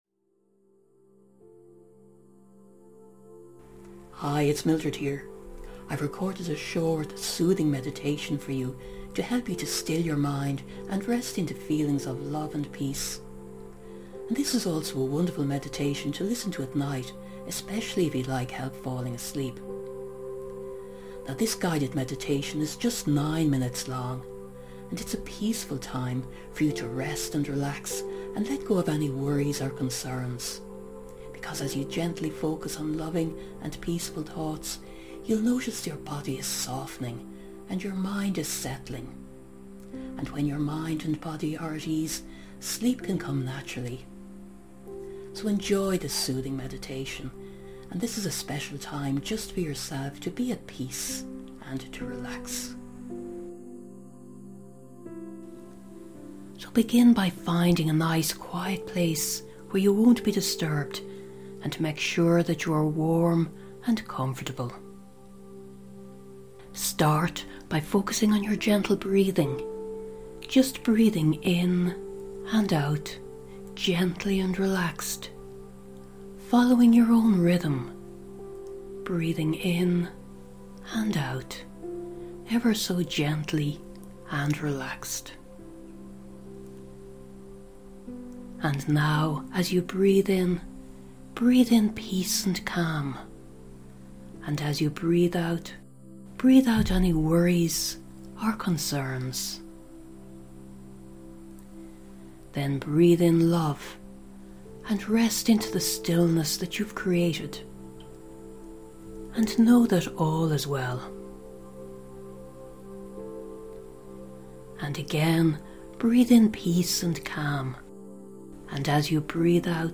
Video: Soothing guided relaxation.
Rest-and-relaxation-to-help-with-sleep-MP3-audio-file.mp3